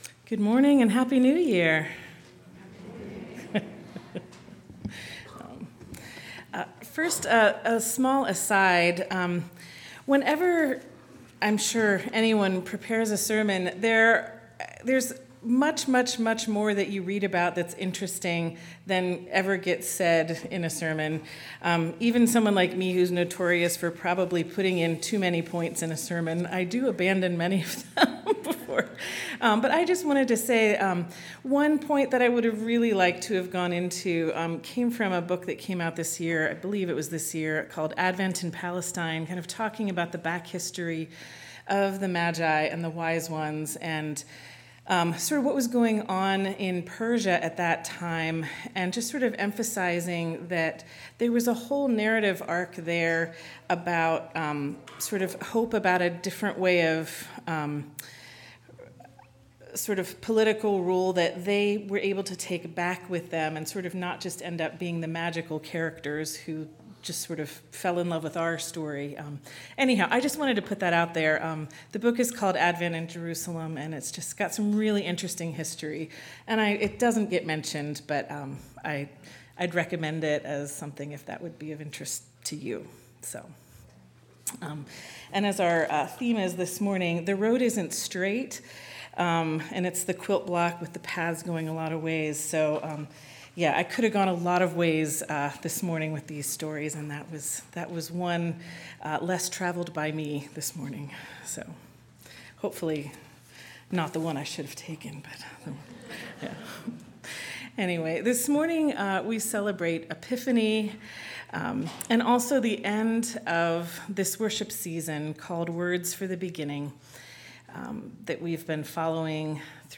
1/5/25 Sermon